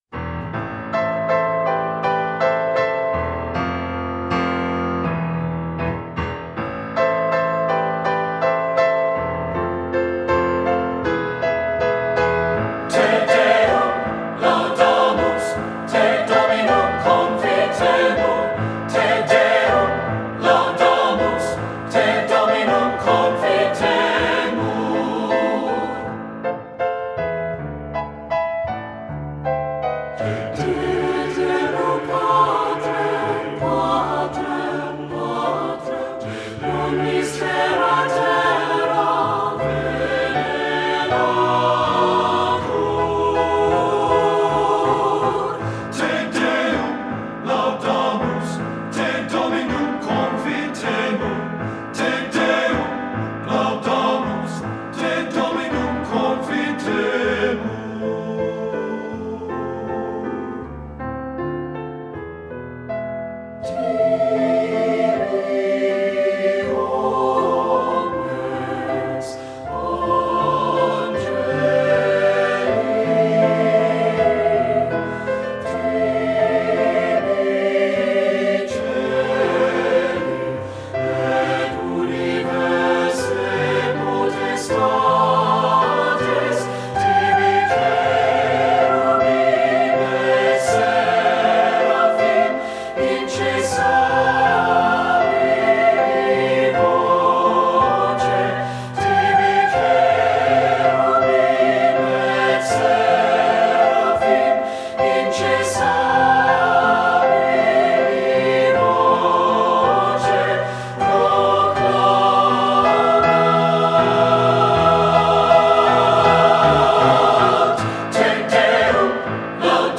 Voicing: SAB and Optional Descant